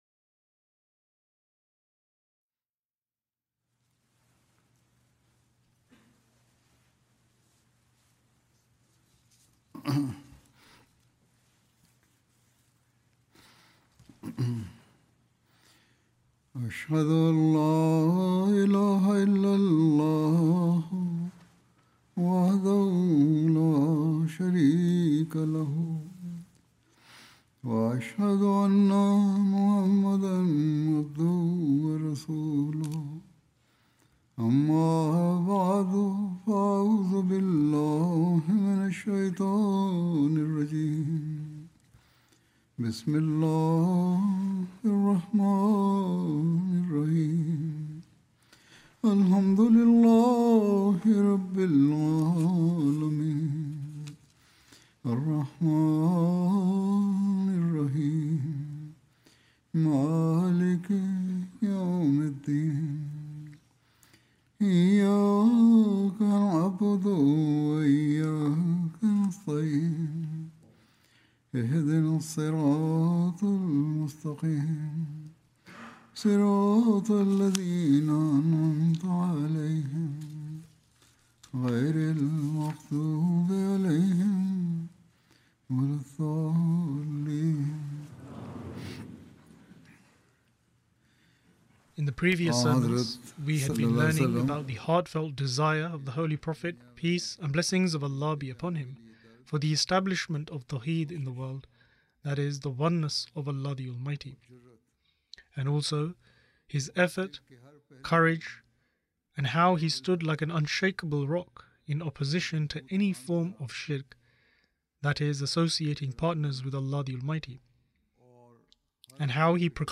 English translation of Friday Sermon (audio)